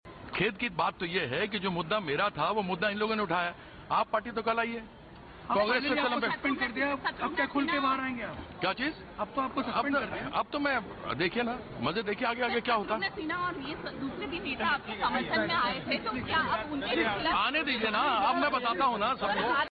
भाजपा सांसद कीर्ति आज़ाद ने ख़ुद को पार्टी से निलंबित किए जाने को दुर्भाग्यपूर्ण बताया है और चेतावनी देने वाले अंदाज़ में ये भी कहा है कि 'आगे आगे देखिए क्या होता है'.